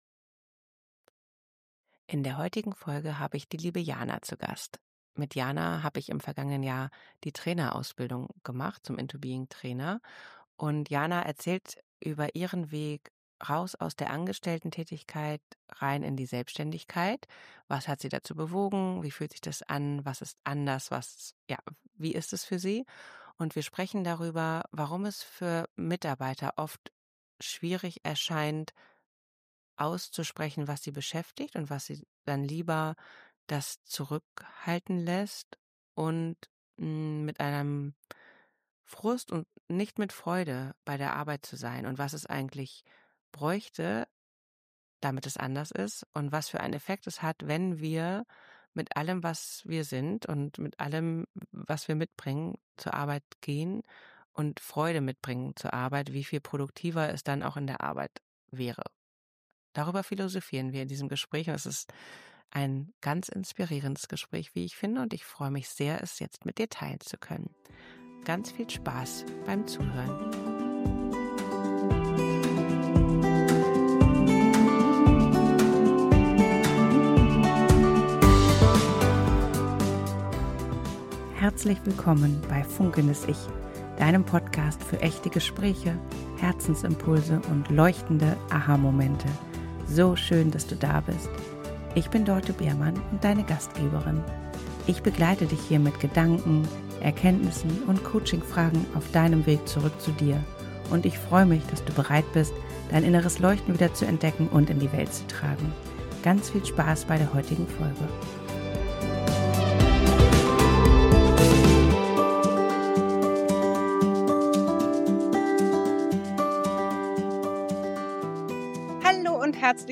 ein Sofa-Gespräch